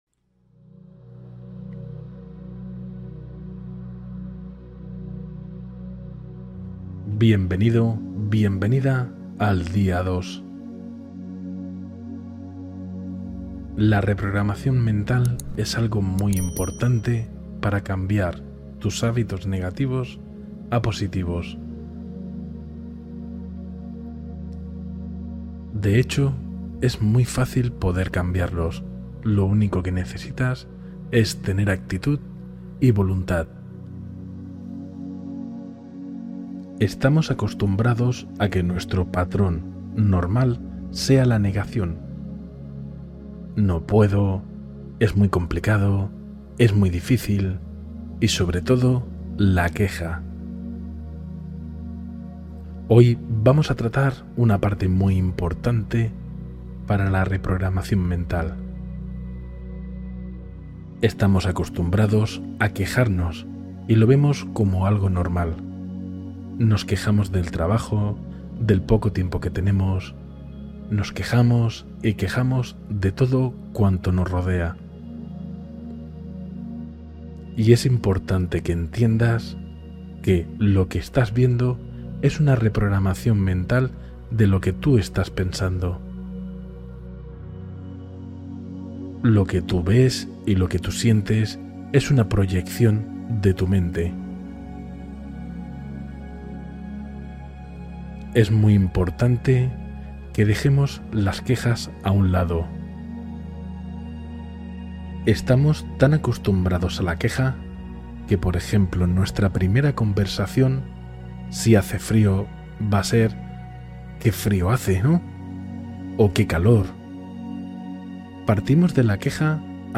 Día 2: meditación guiada para transformar la queja en gratitud y paz